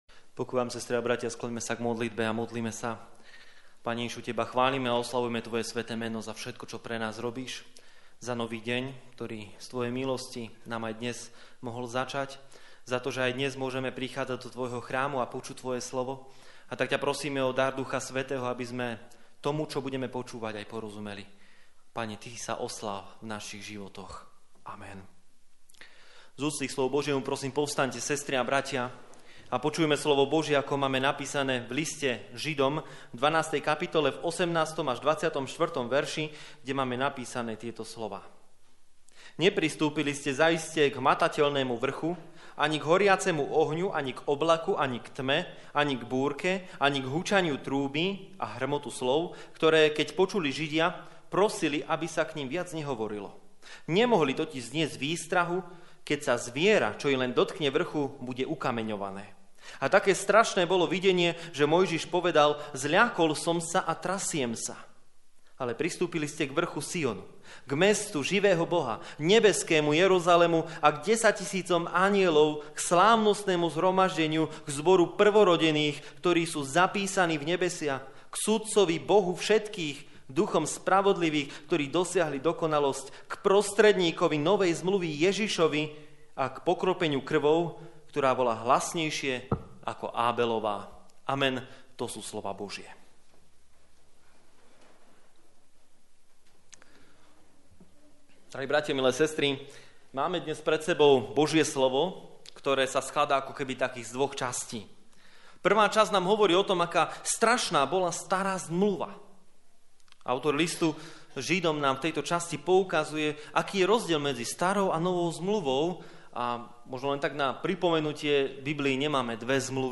24 Service Type: Služby Božie 16. nedeľa po Svätej Trojici O čo sme ustarostení?!